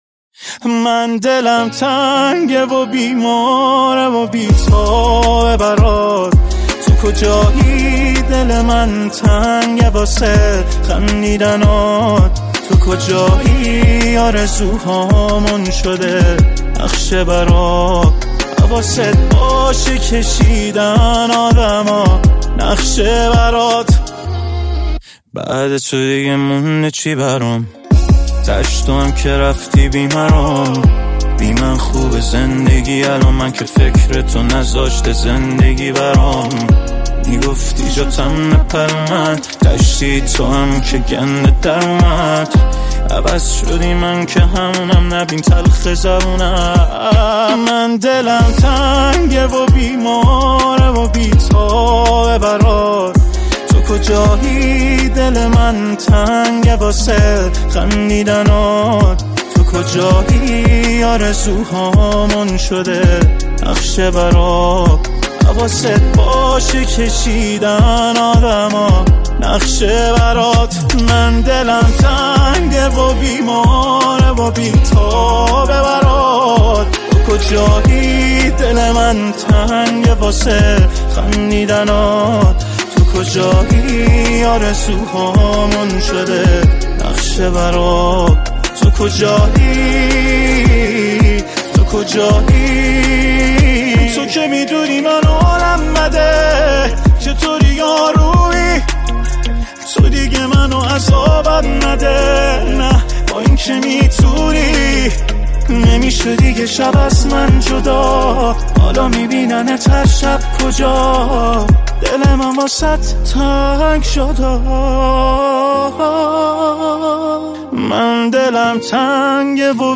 خواننده‌ی سبک پاپ